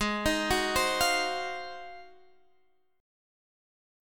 G#13 chord